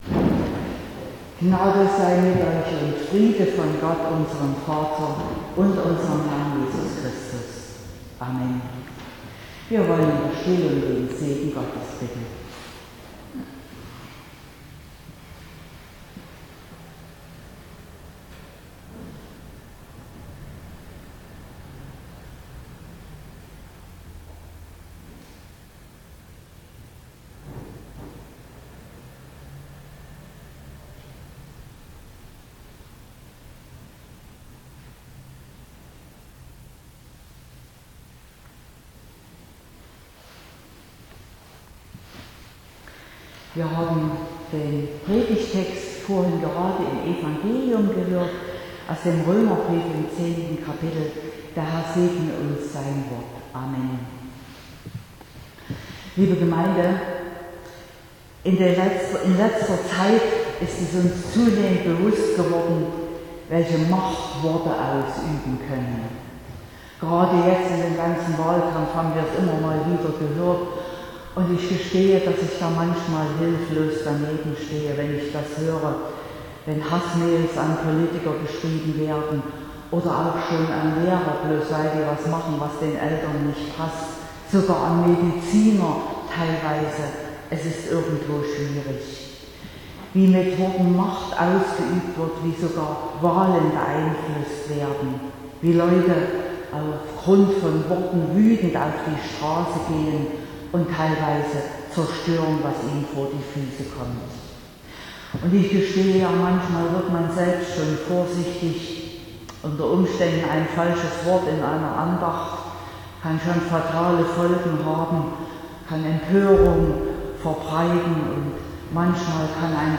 26.09.2021 – Gottesdienst
Predigt (Audio): 2021-09-26_Worte_haben_Kraft.mp3 (28,2 MB)